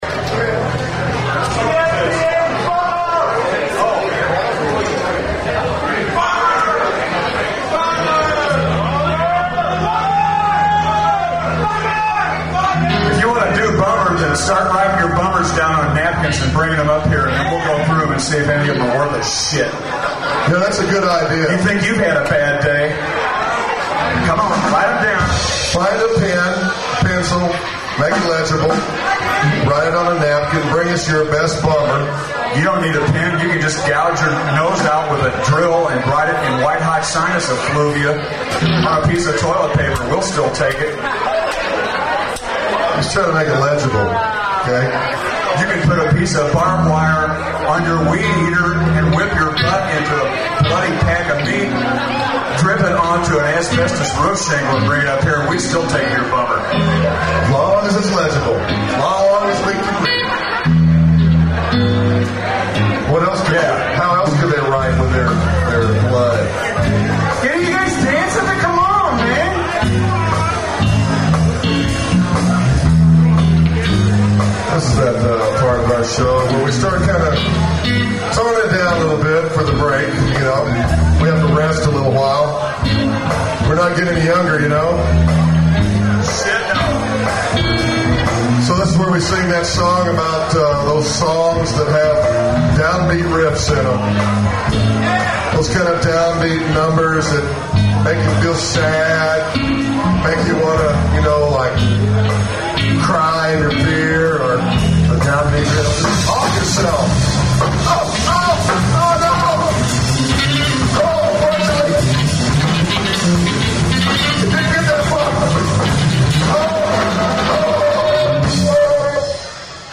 All between song banter preserved.